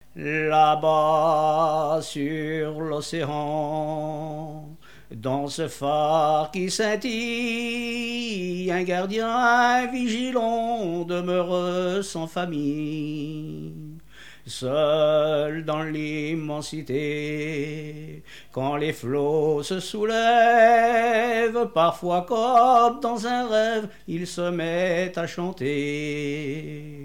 Île-d'Yeu (L')
chansons maritimes contemporaines
Pièce musicale inédite